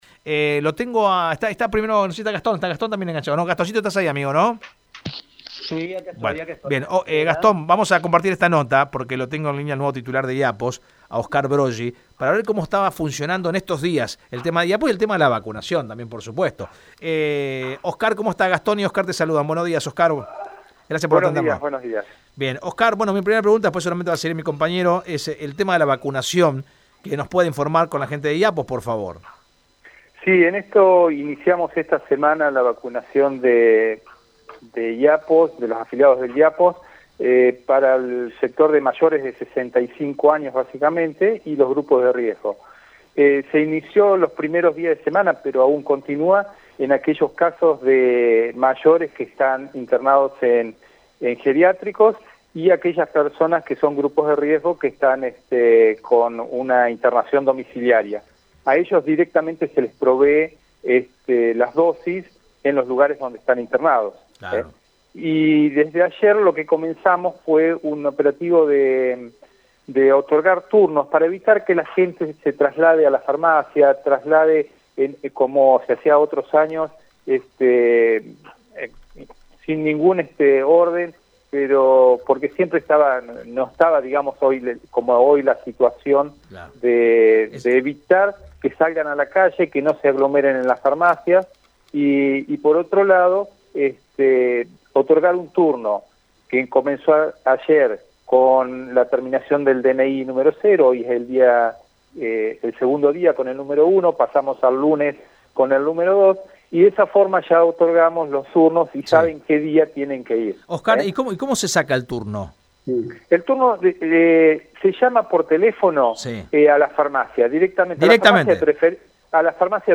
En dialogo con Radio EME, Oscar Broggi, director del IAPOS, brindó detalles de como avanza el programa de vacunación, para todas aquellas personas mayores de 65 años o que son factores de riesgo.